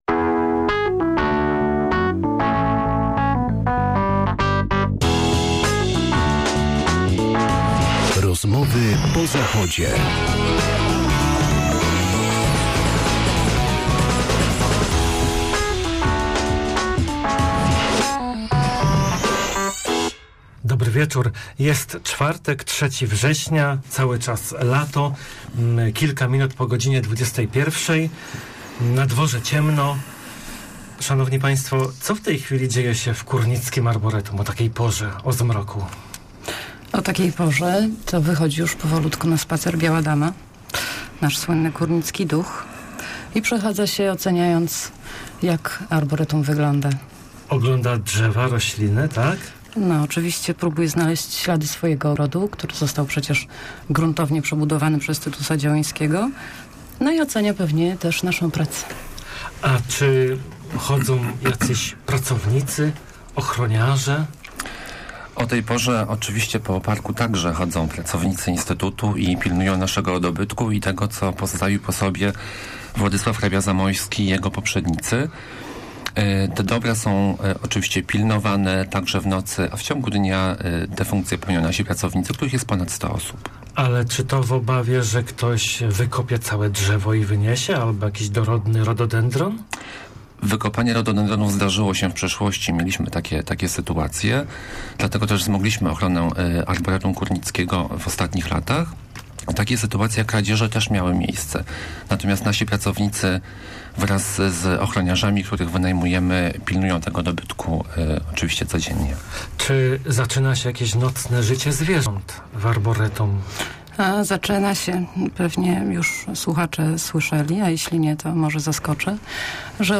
O Arboretum Kórnickim po wichurze w Radiu Merkury - INSTYTUT DENDROLOGII